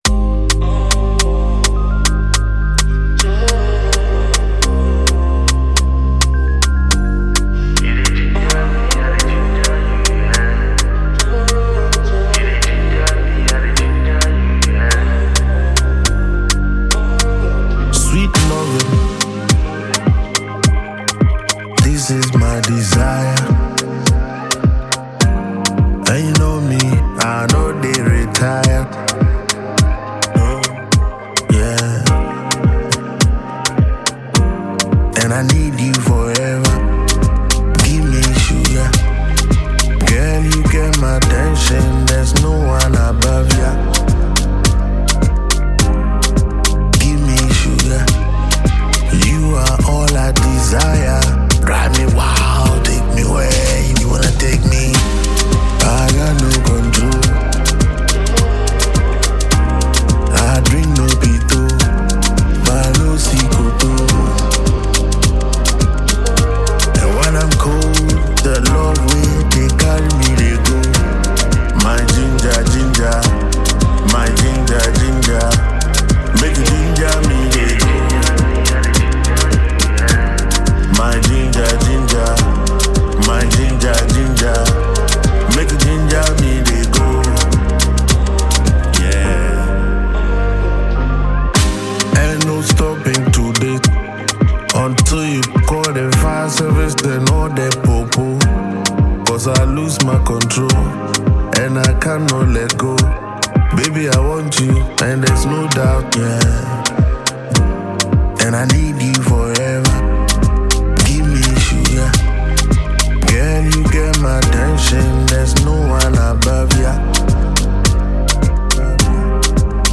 a phenomenal British-based Ghanaian singer
lovely melodies